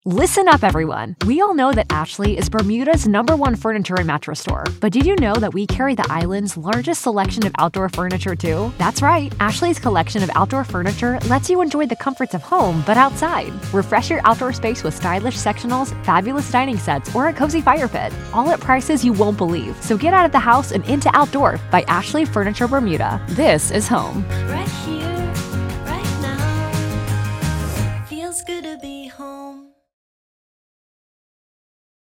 Meine Stimme reicht von einer gesprächigen, lässigen, freundlichen Gen Z über einen charmanten, nahbaren, coolen, komödiantischen besten Freund mit einem kleinen Krächzen bis hin zu einem energiegeladenen, neugierigen, furchtlosen, entzückenden kleinen Jungen.
Mikrofon: Sennheiser MKH 416
SENDEQUALITÄT IM HEIMSTUDIO